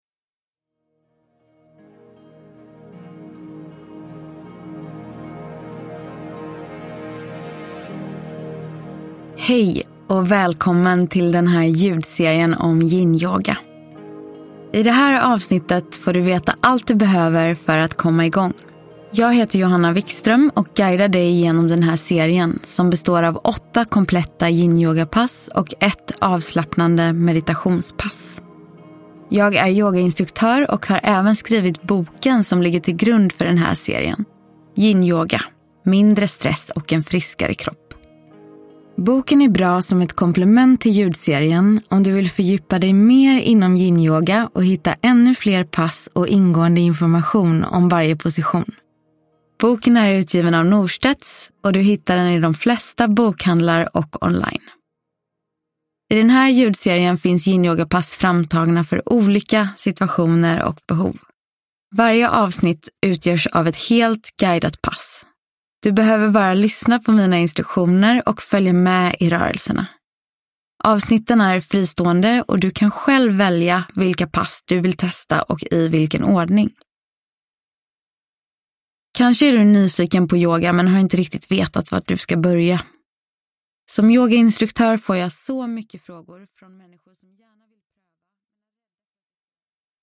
Yinyoga - Allt du behöver veta – Ljudbok